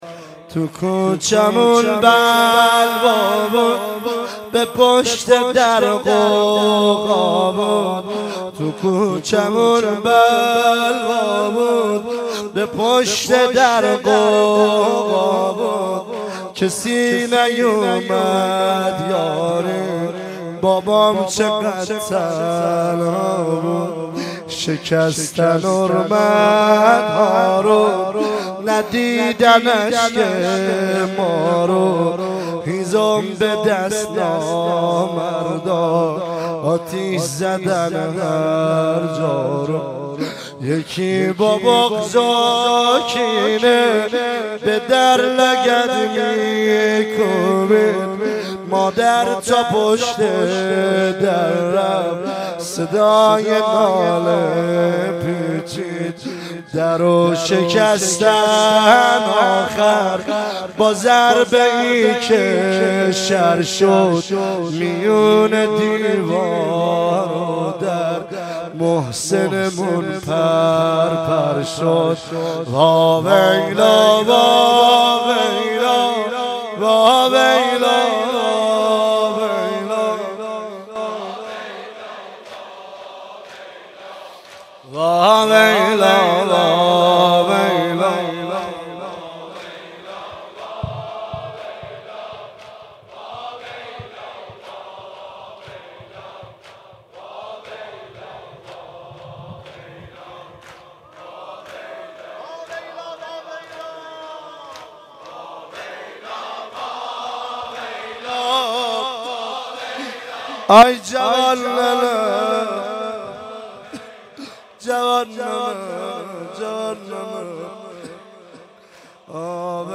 مراسم فاطمیه اول ۹۶